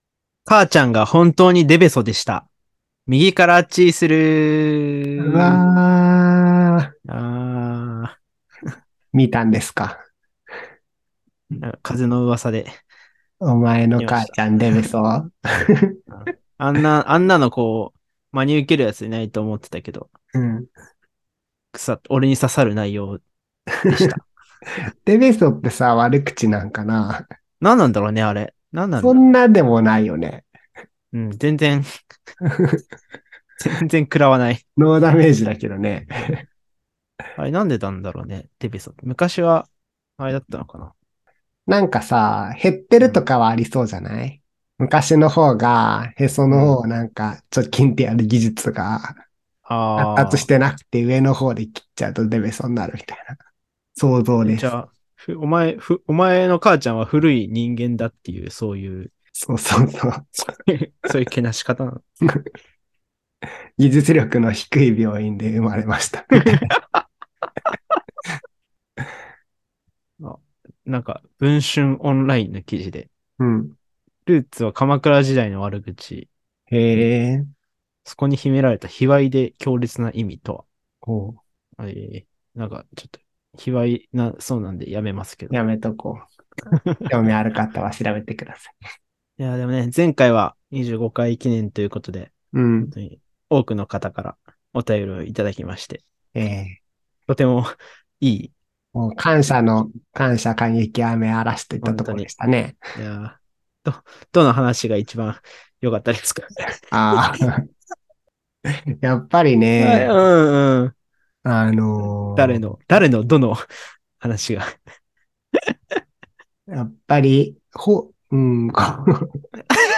あいも変わらずふわっと雑多なトーク！